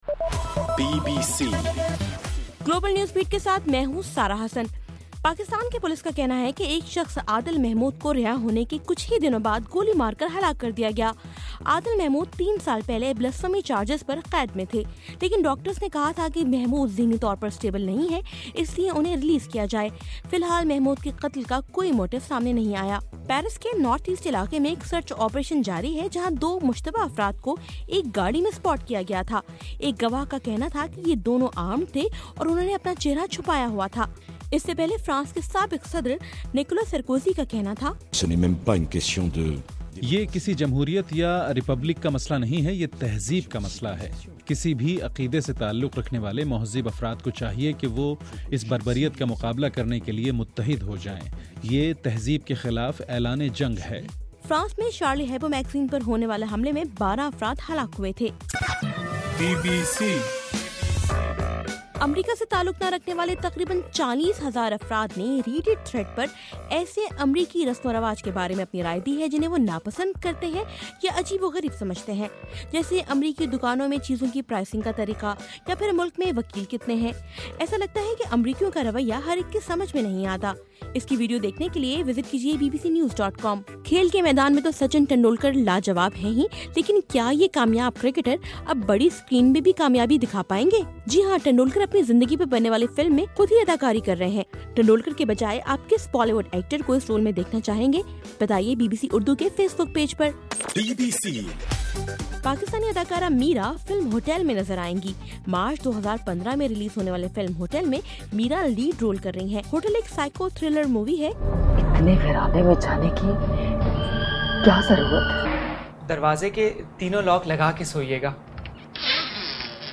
جنوری 8: رات 9 بجے کا گلوبل نیوز بیٹ بُلیٹن